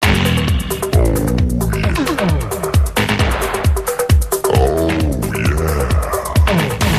Play, download and share yeahy original sound button!!!!
oooh-yeah-mp3cut.mp3